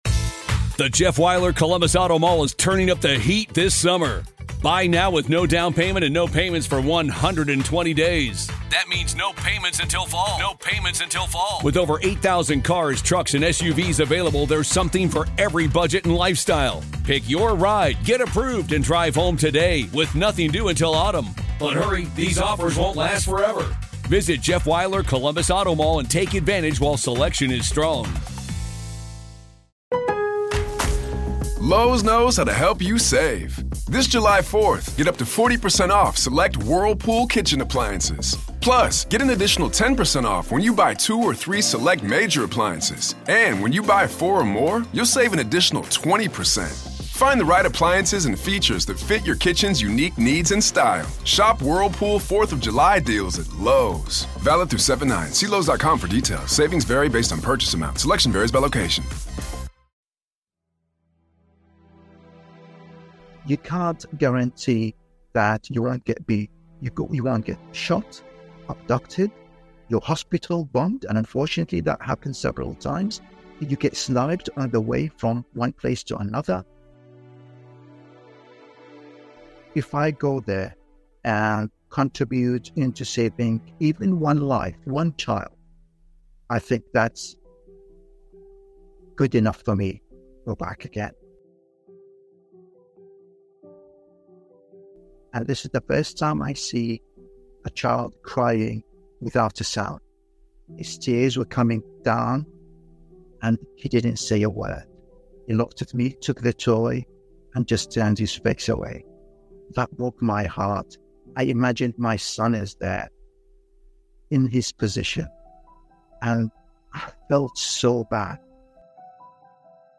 Humanitarian Extraordinaire: An Interview